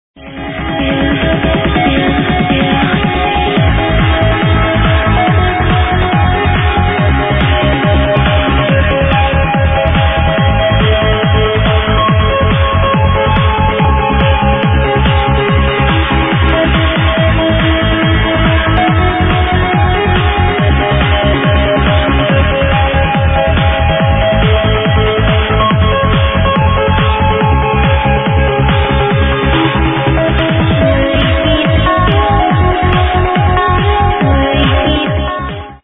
Trance Tune